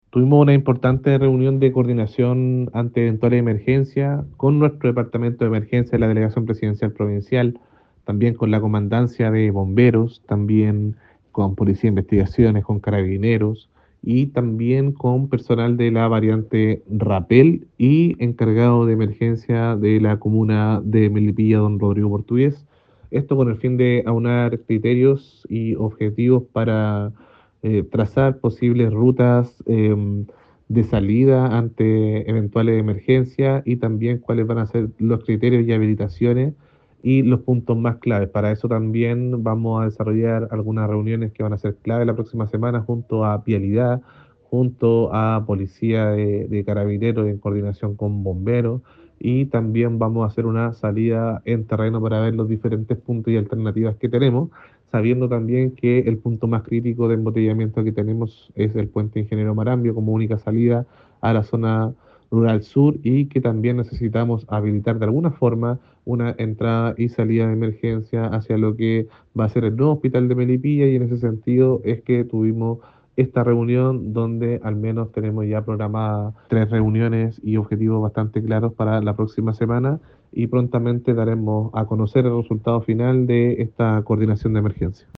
CUNA-DELEGADO-.mp3